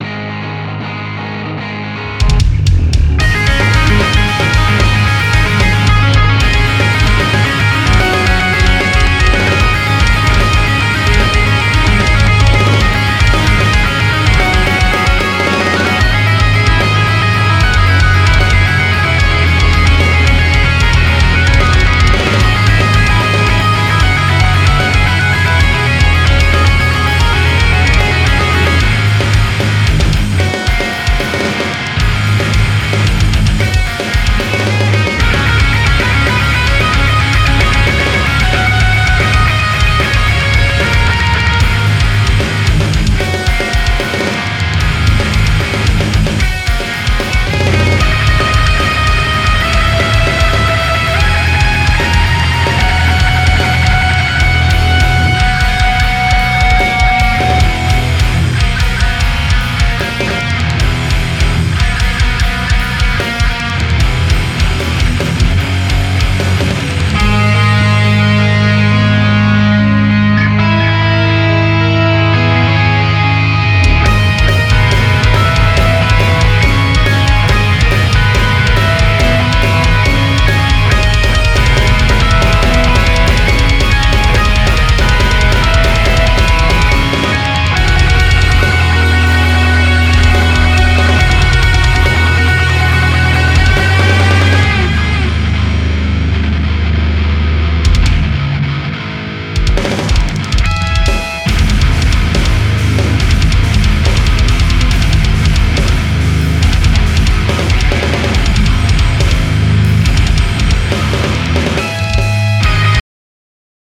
We have recorded our upcoming song at home. It sounds not so heavy and fat. For example the guitars sounds too digital but i used impulse response and its not fat enough.
The instruments not blend together or i dont know whats the problem here...